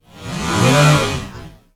ROBOTIC_Movement_03_mono.wav